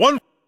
flipperRollCount1.ogg